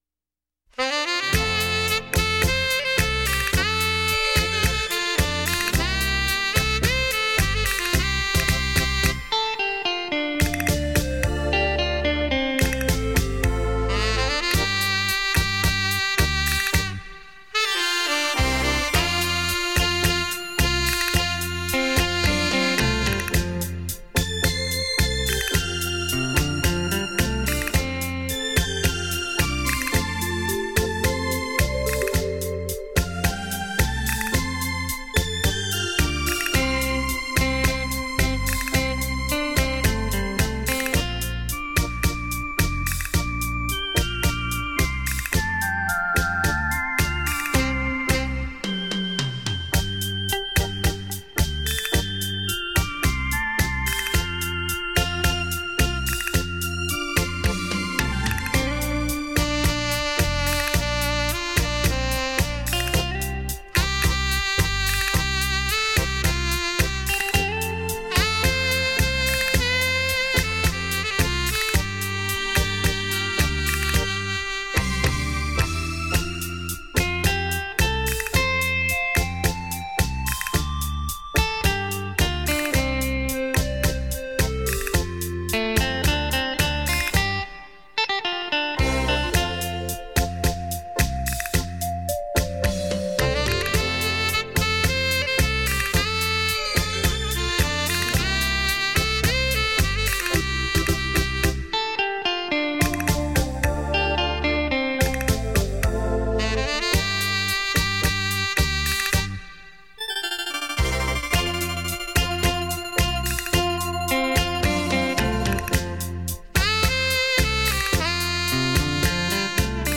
雷射版[音响测试带] 现场演奏